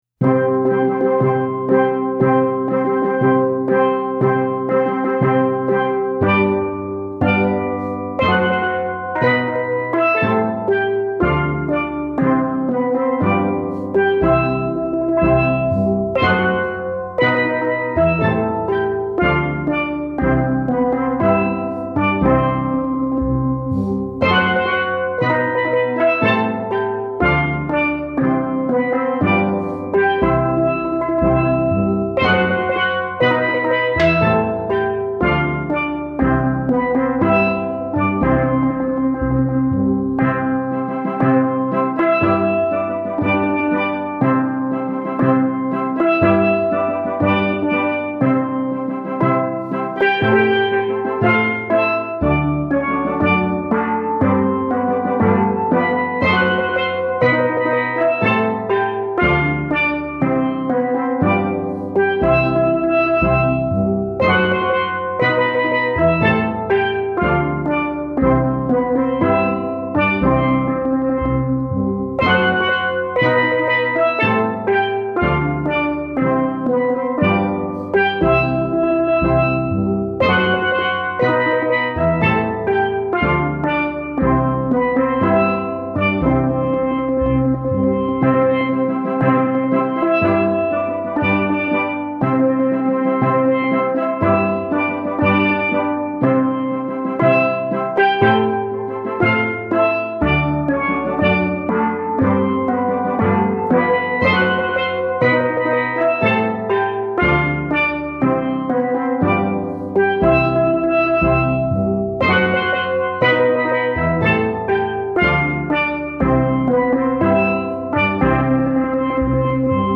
Steel Drum Wedding
selections played on island steel